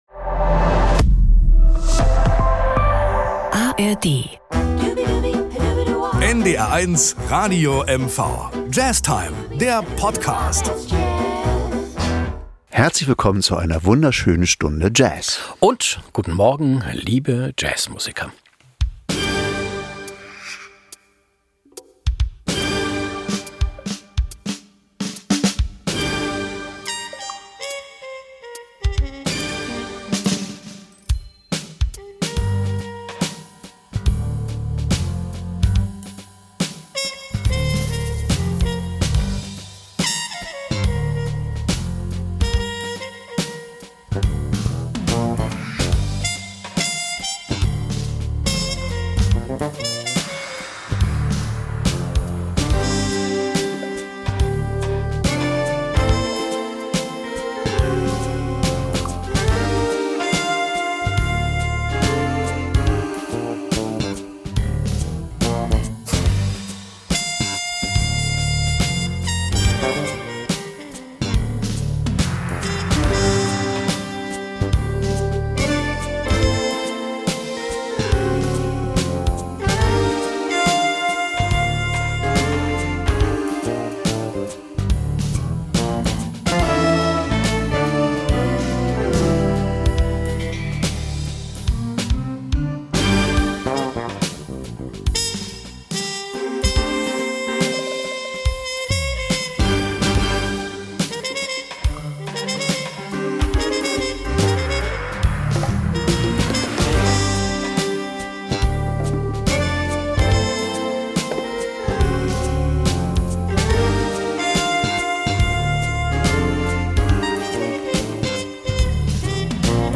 Das LIVE – Anspiel diesmal ist : „I‘ll be seeing you“ – eine Komposition von Sammy Fain aus dem Jahr 1938.